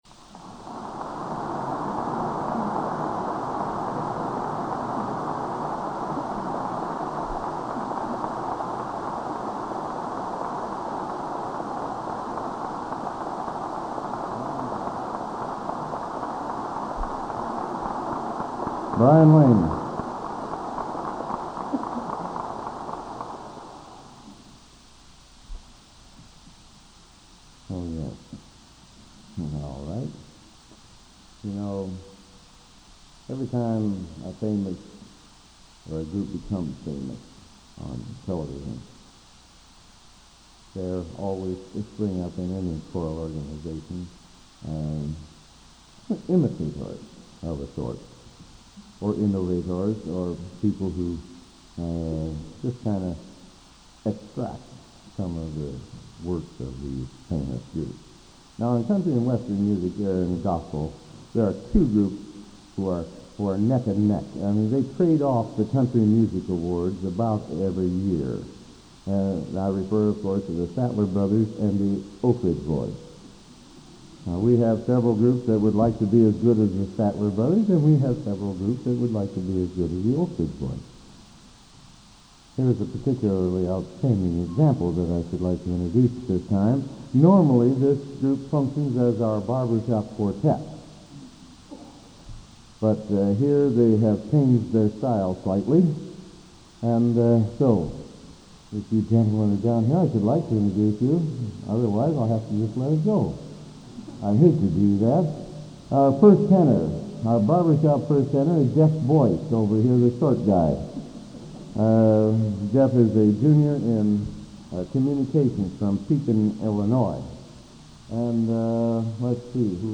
Collection: Broadway Methodist, 1982